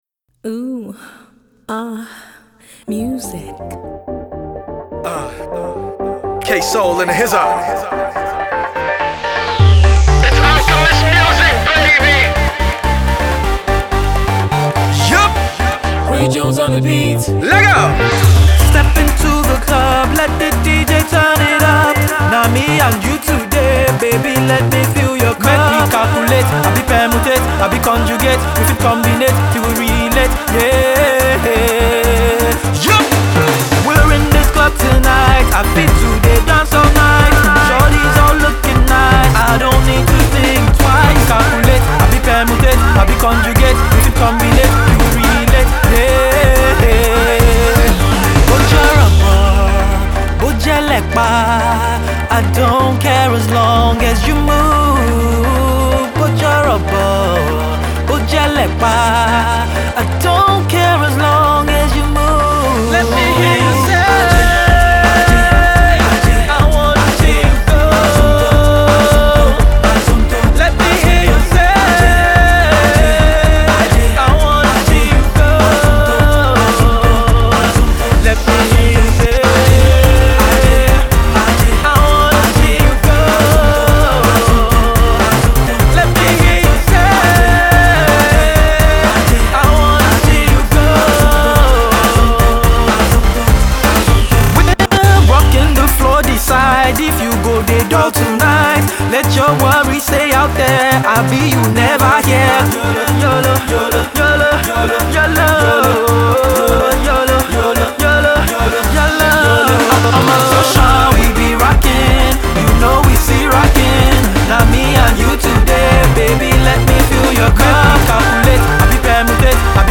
contemporary West African Afro Pop
very uptempo almost Euro-Dance production